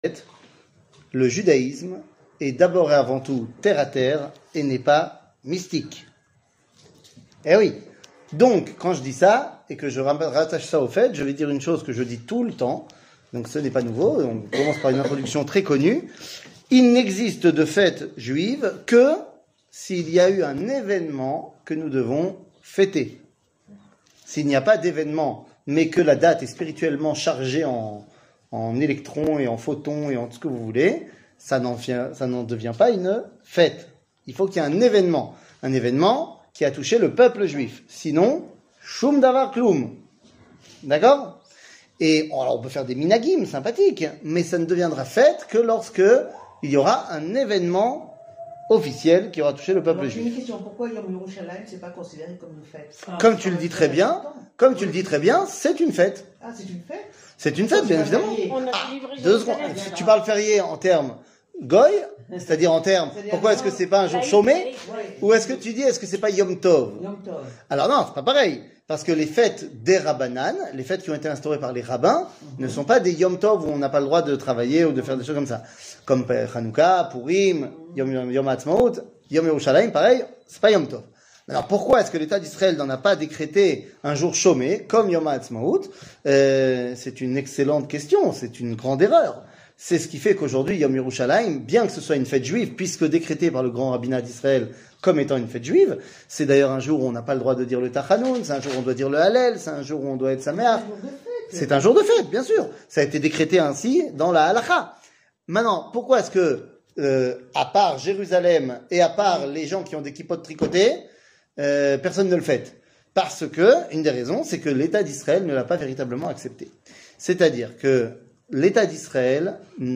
שיעור מ 25 מאי 2023 45MIN הורדה בקובץ אודיו MP3